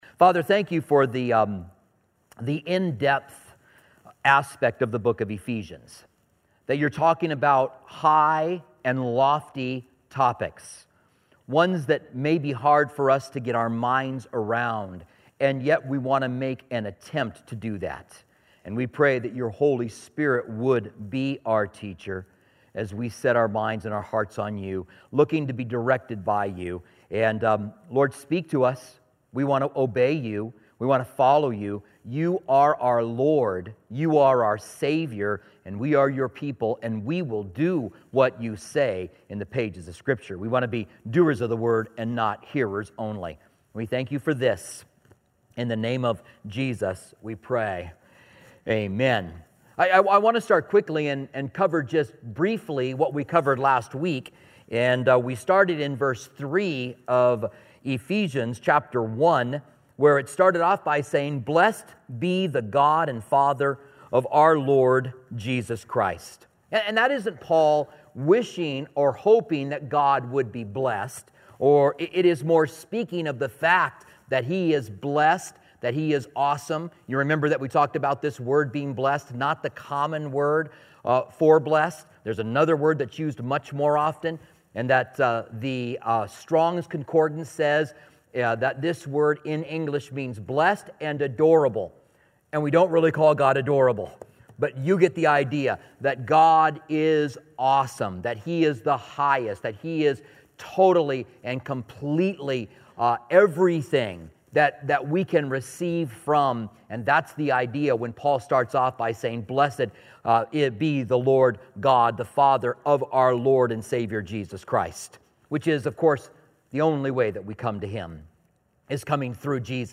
Commentary on Ephesians